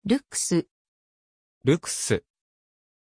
Aussprache von Lux
pronunciation-lux-ja.mp3